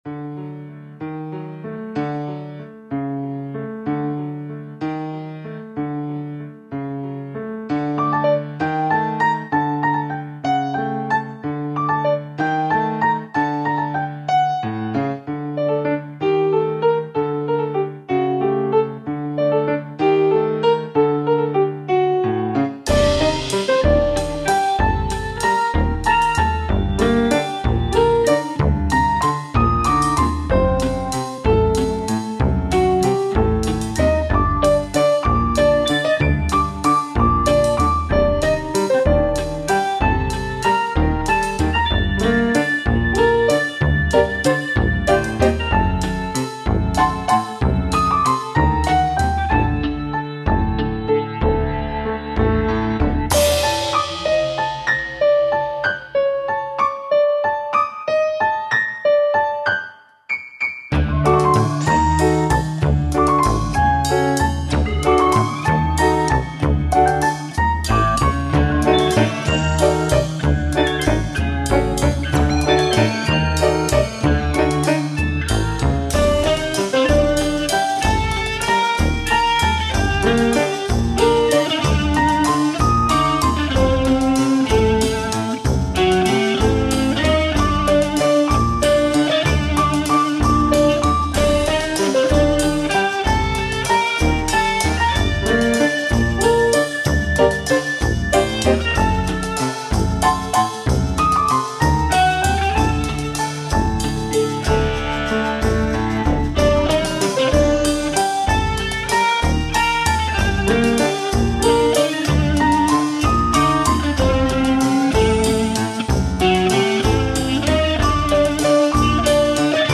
Οργανικό κομμάτι